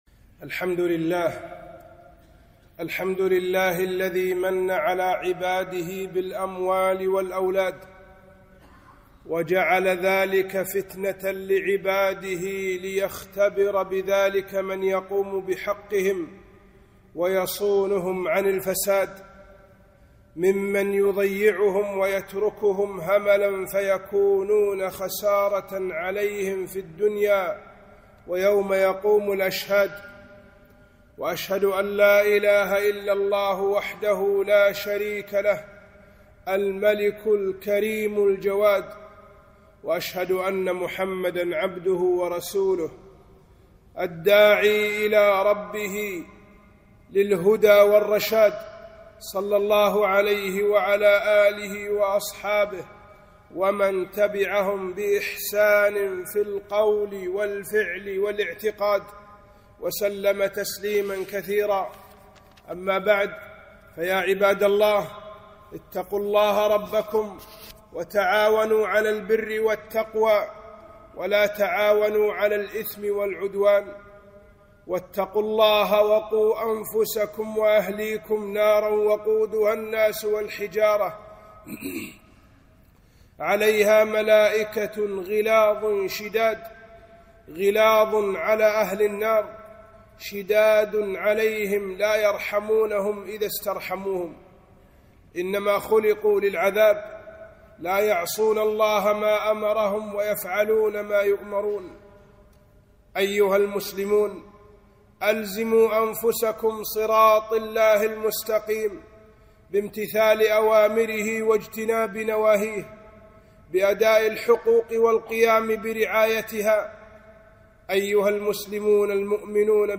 خطبة - تربية الأبناء